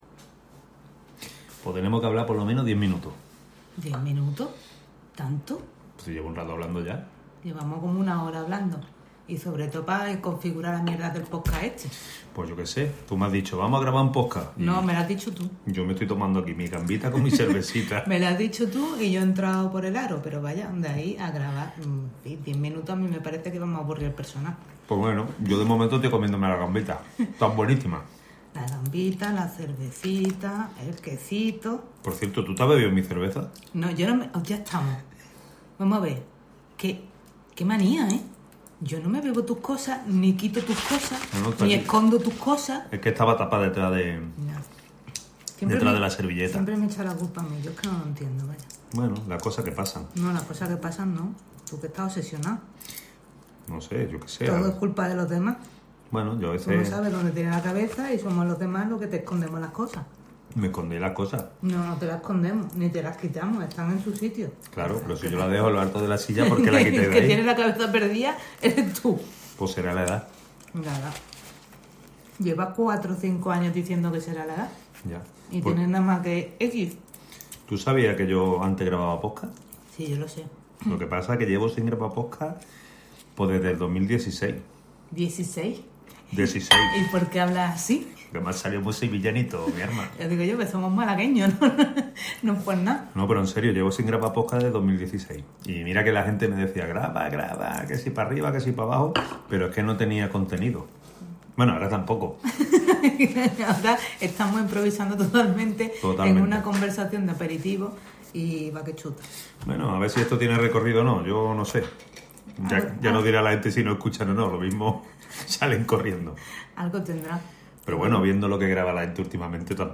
Un día, a la hora del vermú, nos dió por grabarnos mientras nos tomábamos el aperitivo.